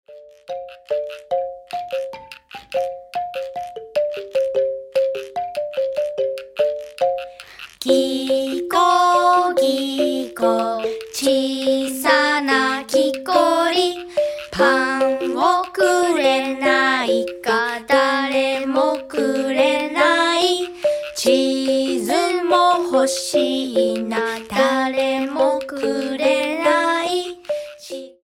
世界のわらべうたを楽しむ
素朴で心休まる音楽です。
南米のわらべうた